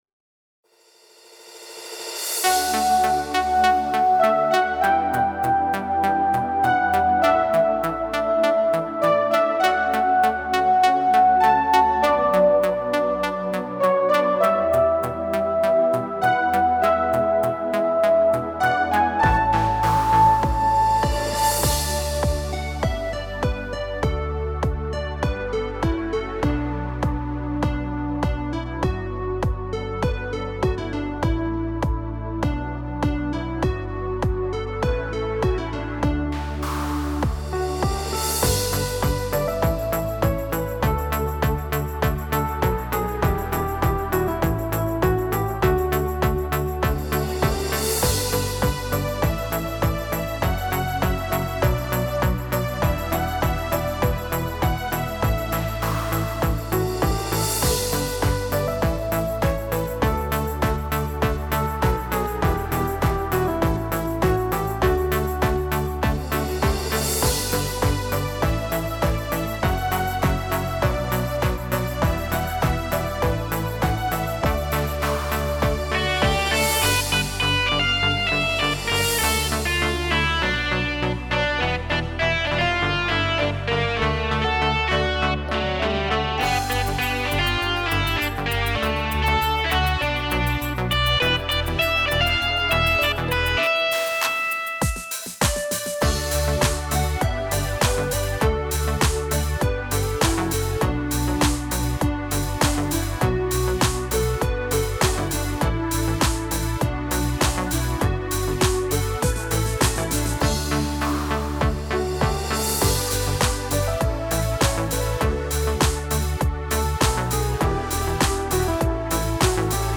•   Beat  02.